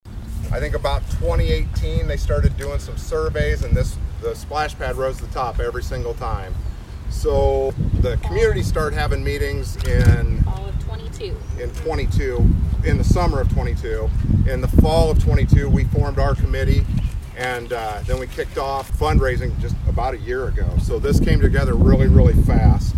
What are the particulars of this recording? Groundbreaking ceremony held for the Atlantic SplashPad Project